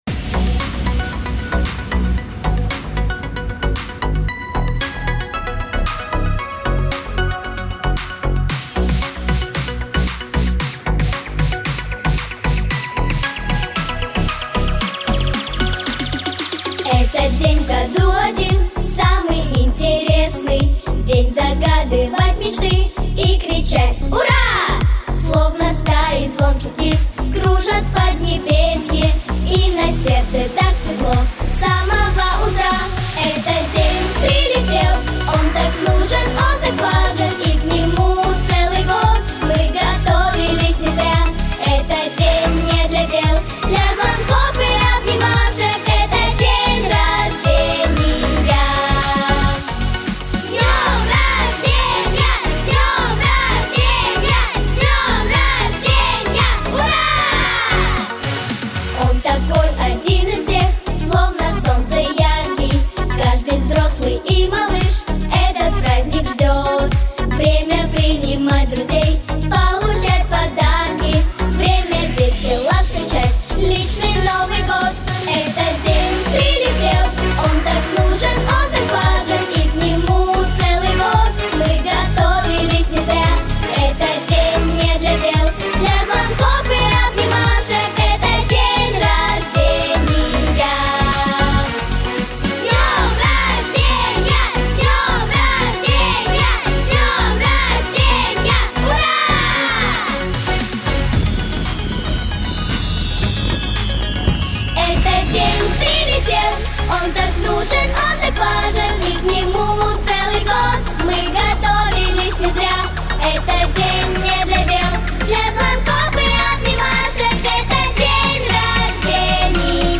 Яркая весёлая песня для детского праздника.
Характер песни: весёлый.
Темп песни: быстрый.
Диапазон: Си малой октавы - До♯ второй октавы.